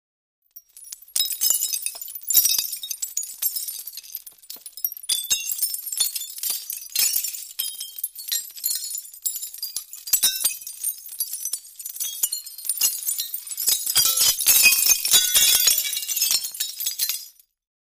Звуки стекла
Осколки стекла падают